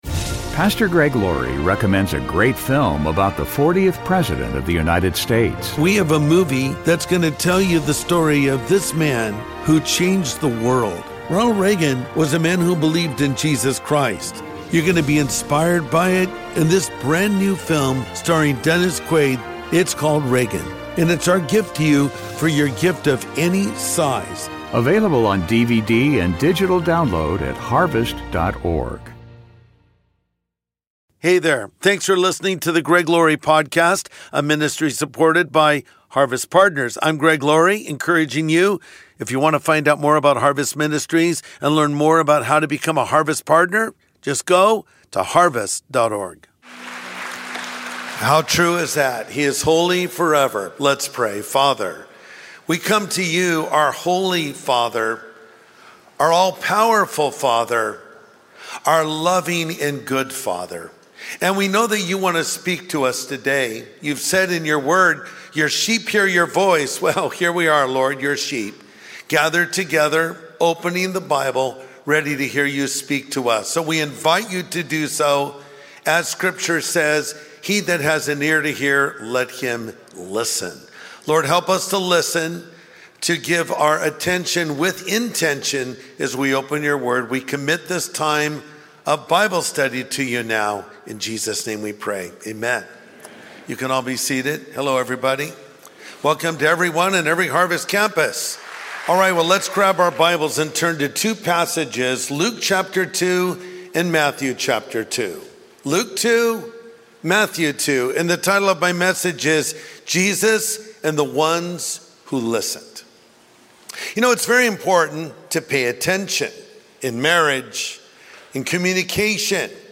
Jesus and the Ones Who Listened | Sunday Message Podcast with Greg Laurie